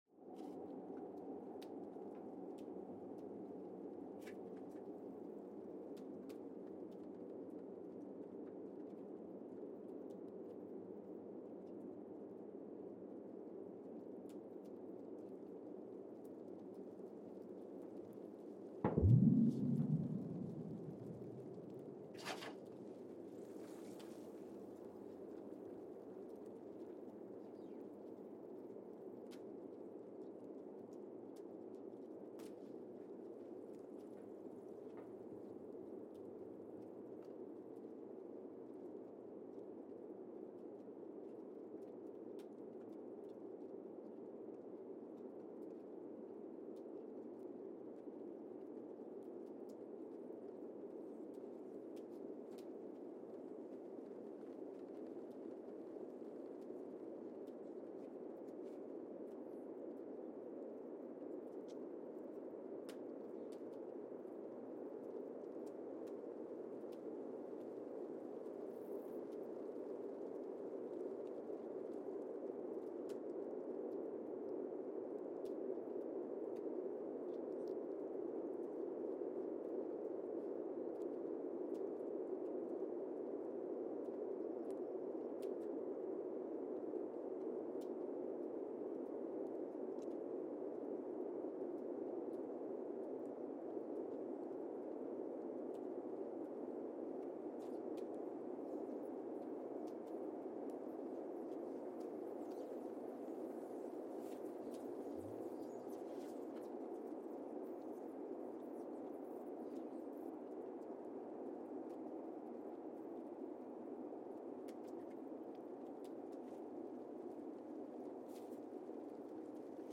Casey, Antarctica (seismic) archived on May 3, 2025
Station : CASY (network: GSN) at Casey, Antarctica
Sensor : Streckheisen STS-1VBB
Speedup : ×1,800 (transposed up about 11 octaves)
Loop duration (audio) : 05:36 (stereo)
SoX post-processing : highpass -2 90 highpass -2 90